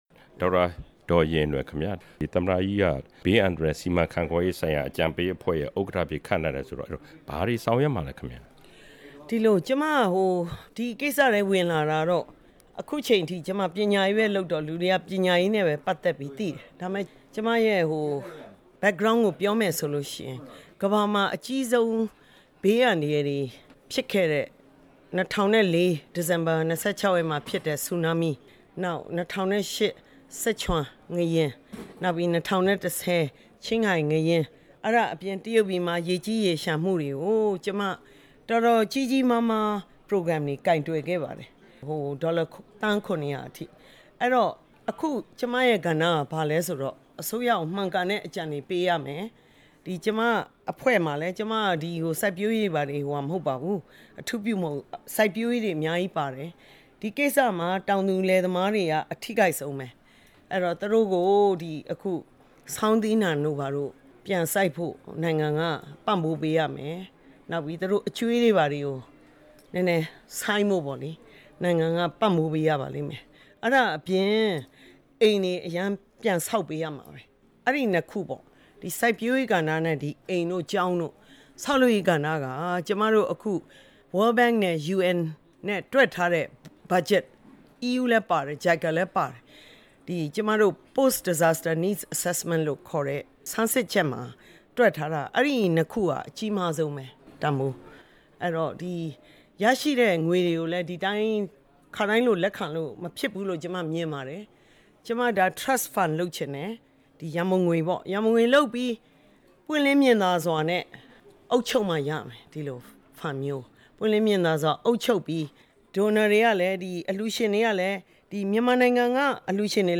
ဒေါက်တာဒေါ်ရင်ရင်နွယ်နဲ့ မေးမြန်းချက်